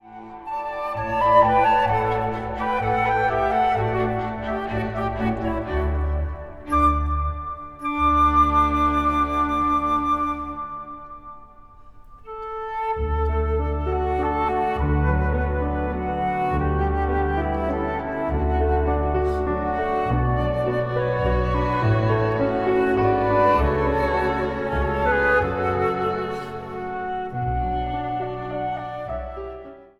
Prachtige instrumentale muziek
strijkorkest
Instrumentaal | Dwarsfluit
Instrumentaal | Orkest